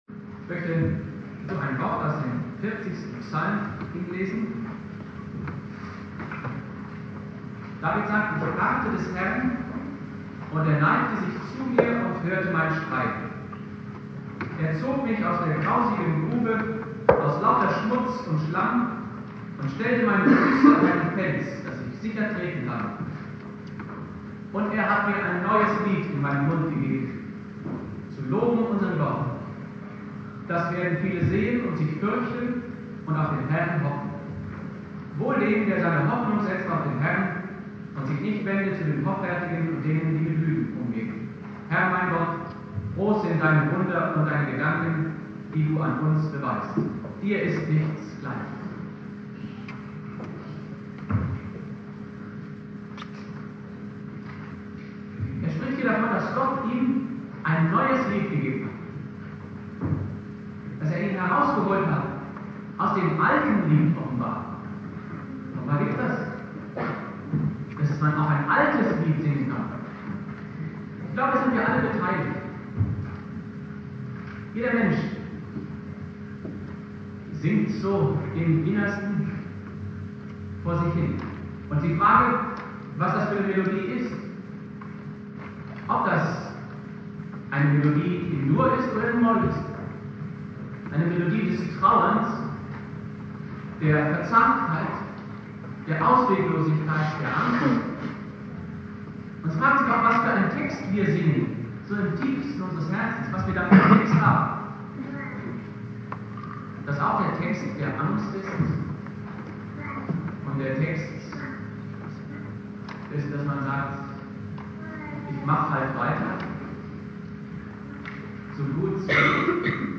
Predigt
Gottesdienst mit Singeteam